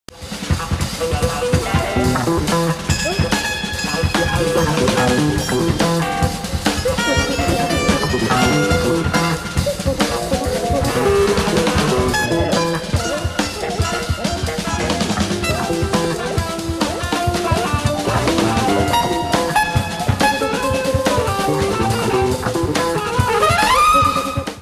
サウンドボード録音
誰が聞いてもこれがブートとは信じ難い音質でマニアも納得のノー・カット盤になります。